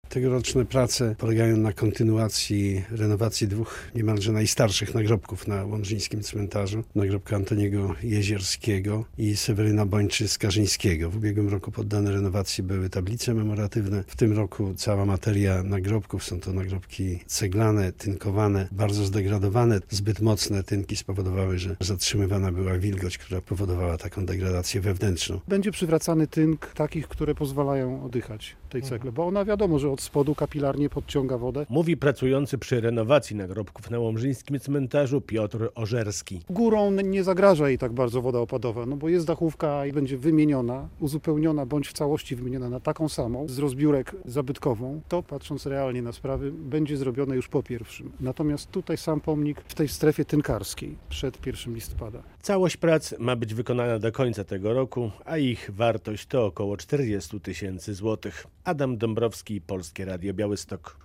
WIADOMOŚCI ŁOMŻA: Uratowane przed zniszczeniem.